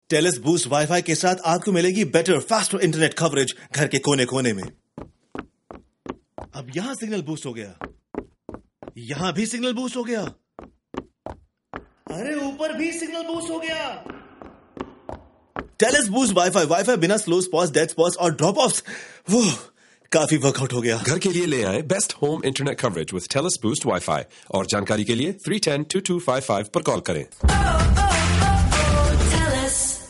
To promote its Boost internet services, binaural radio ads in Cantonese, Mandarin, Hindi and Punjabi moved its narrator around the left and right stereo channels to make it sound like they were moving around the listener’s house, marveling at the signal strength.